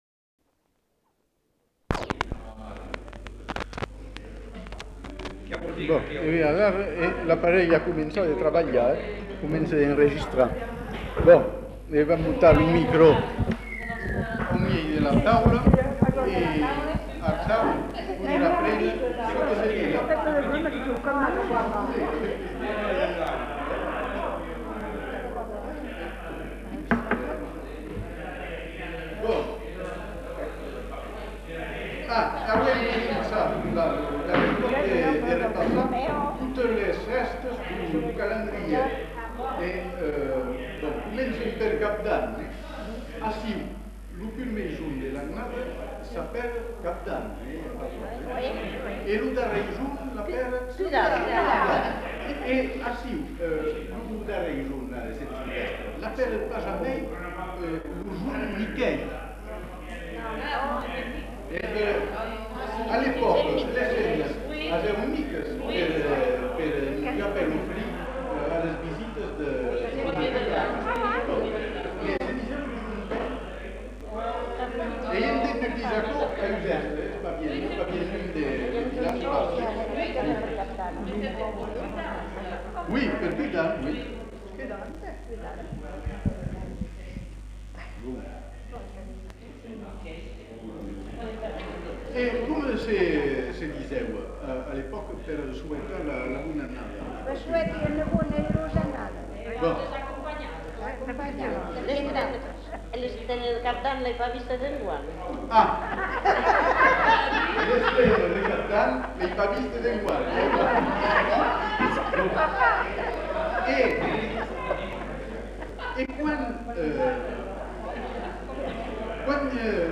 (enquêteur)
Lieu : Villandraut
Genre : témoignage thématique